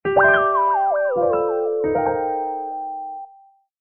gameover.mp3